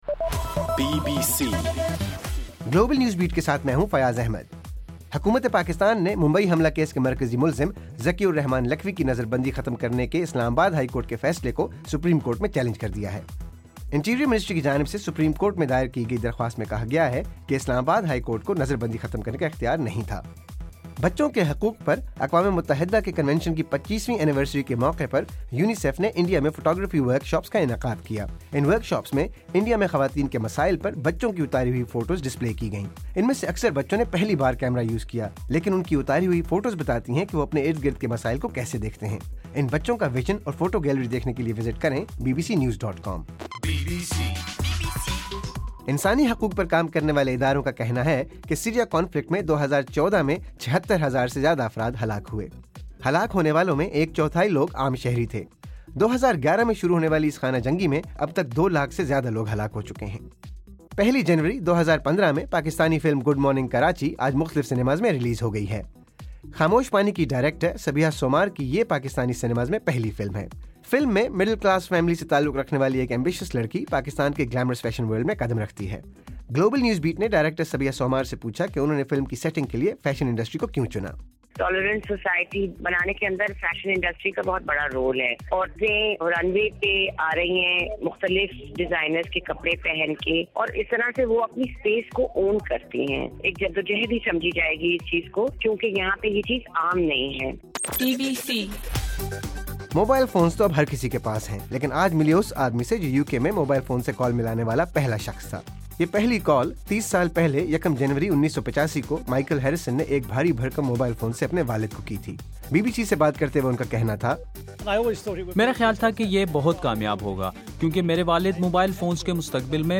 جنوری 01: رات 12 بجے کا گلوبل نیوز بیٹ بُلیٹن